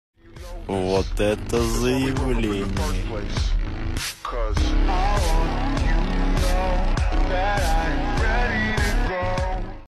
vot eto zaiavlenie Meme Sound Effect